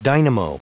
Prononciation du mot dynamo en anglais (fichier audio)
Prononciation du mot : dynamo